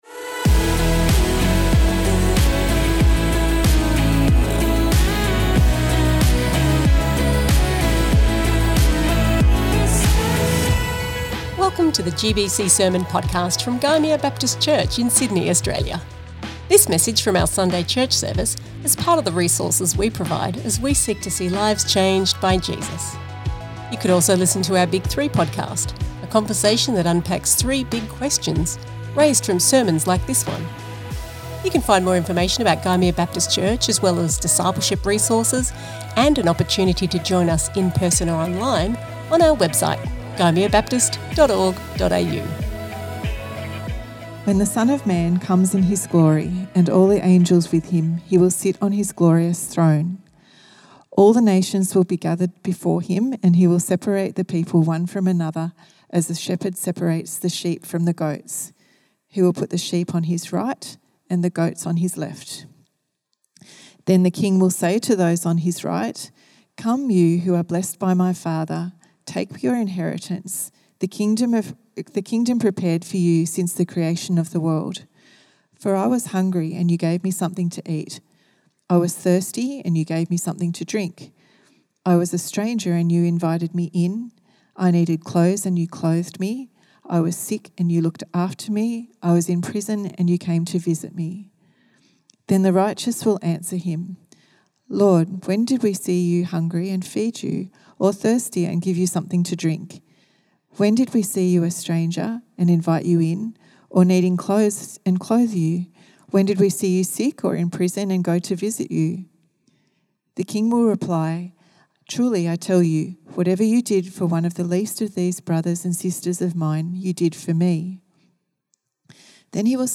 This message from our Sunday church service is part of the resources we provide as we seek to see lives changed by Jesus.